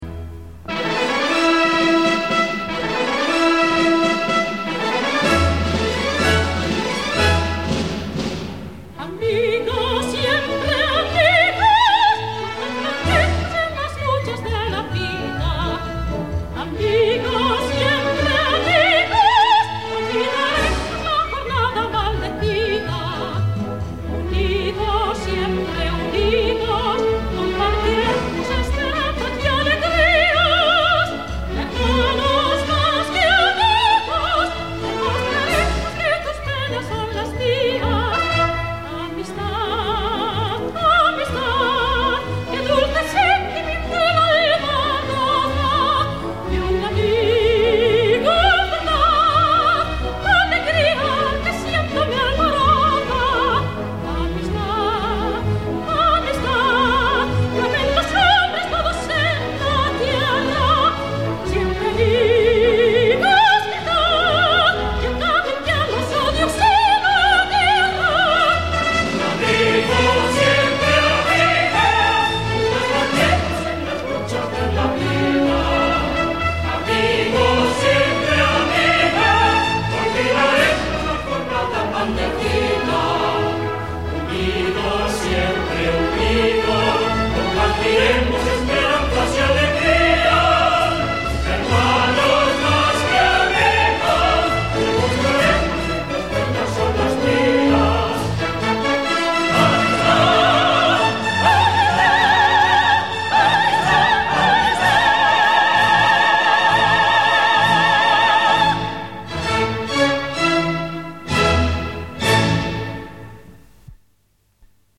Amigos siempre amigos: Toñy Rosado, coro cantores de Madrid, Gran Orquesta Simfónica, director: Ataulfo Argenta.
Aunque la música no es de lo mejor de Jacinto Guerrero, la letra de de José Ramos Martín sintetiza muy bien este agradecimiento a los amigos, si bien dentro del contexto de la obra tiene un significado un poco crítico, pero extraída de la zarzuela es un bellísimo canto a la amistad.
La inolvidable mezzo Toñy Rosado, a quien tuve el honor de escuchar en persona en el estreno en el Liceu de la ópera de Angel Barrios “La Lola se va a los puertos” (diciembre de 1955), al lado de Manuel Ausensi, Carlos Munguía y de la añorada contralto Pilar Torres, lidera este himno a la amistad con su voz oscura y potente (tenía un volumen excepcional).
Esta marcha es preciosa; solíamos ver cada año el concierto que ofrecía en el Auditorio de Madrid Plácido Domingo en la víspera del día de Reyes, pero conocíamos la marcha en la voz de tenor y del coro, pero no interpretada por una mezzo.